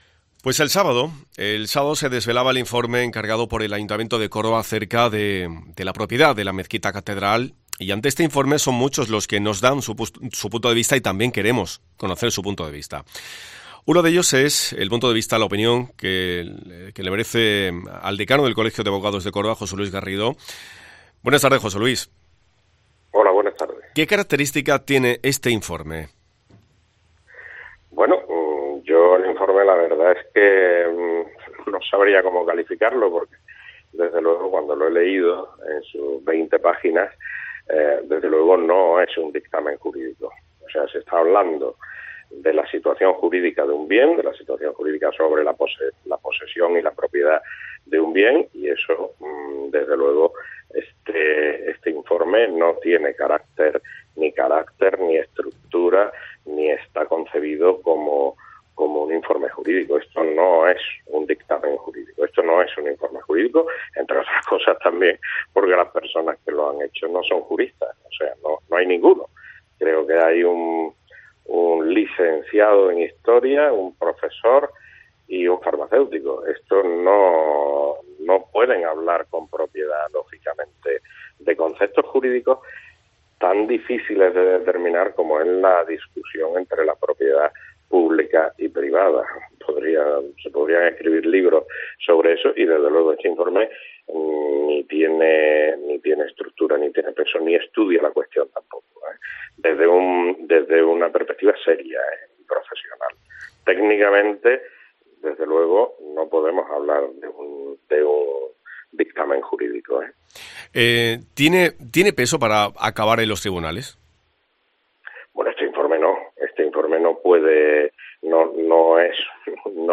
Valoración de tres expertos sobre el informe de la Mezquita-Catedral